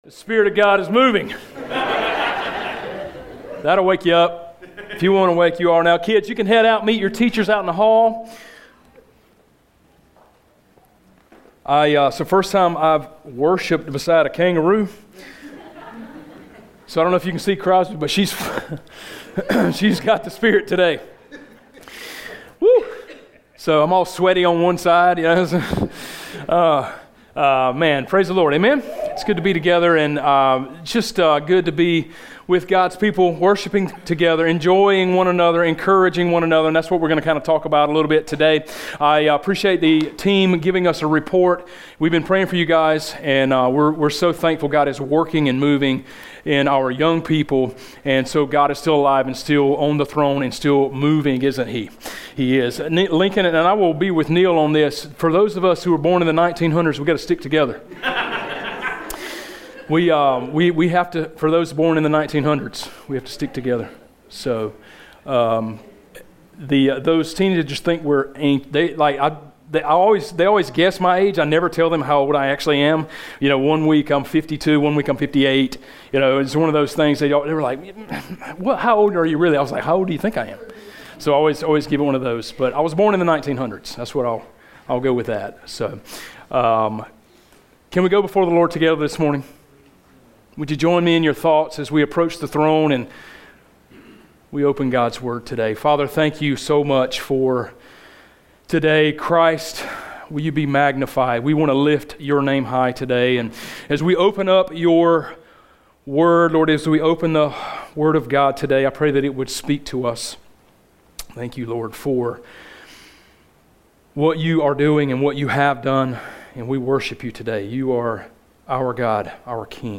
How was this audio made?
Macedonia Baptist Church is a welcoming, loving church that teaches biblical truth.